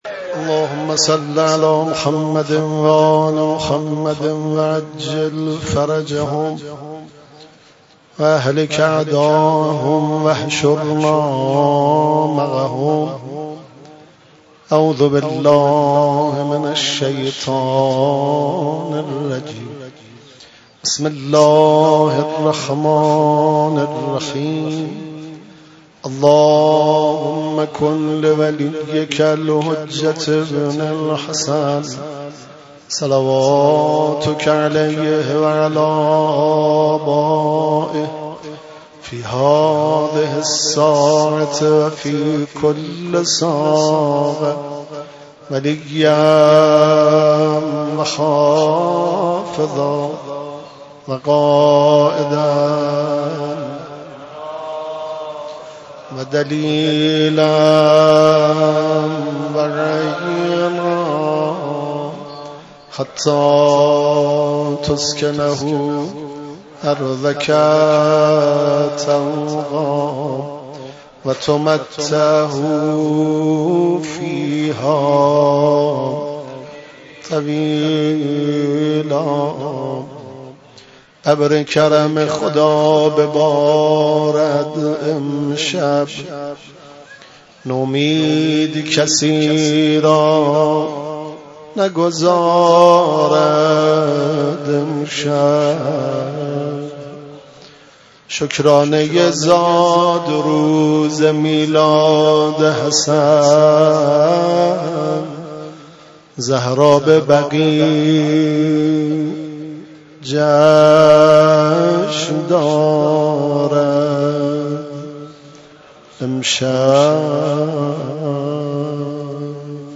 [قرائت دعای مجیر و زیارت امین الله ، مدح امام حسن مجتبی (علیه السلام)]